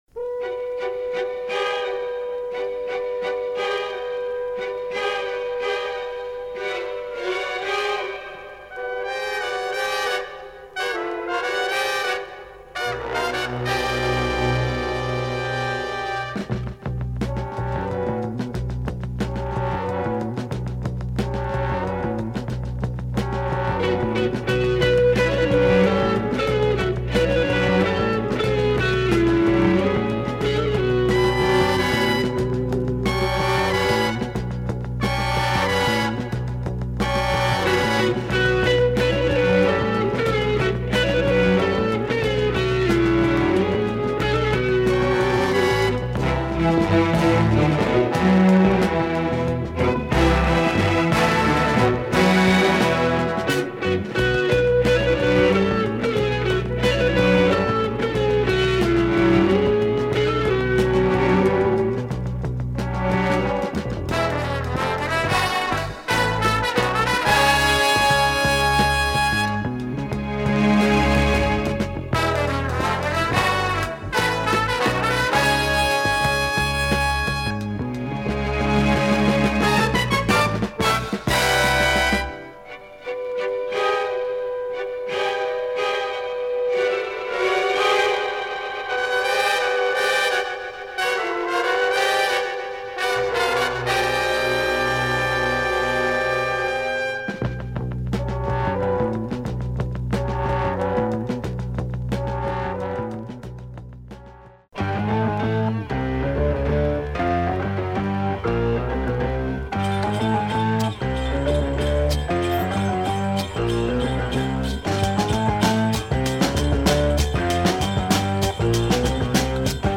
on a groovy pop